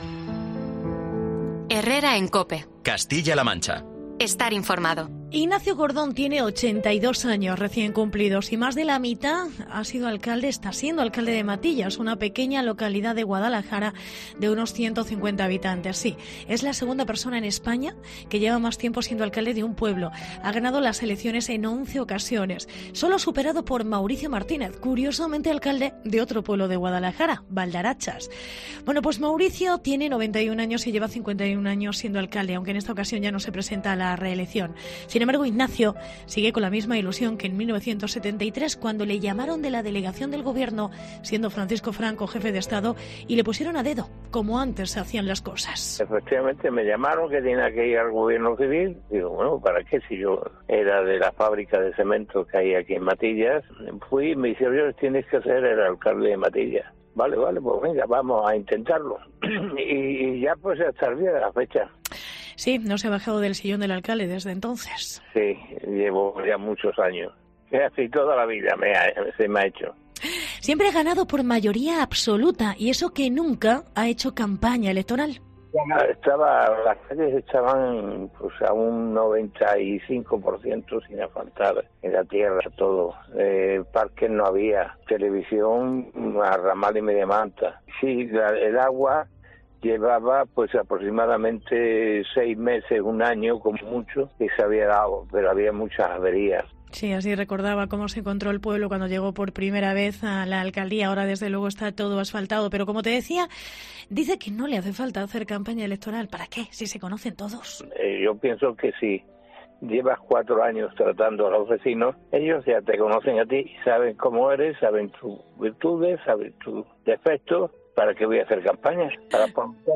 Entrevista a Ignacio Gordón, uno de los alcaldes que más años lleva en el cargo en España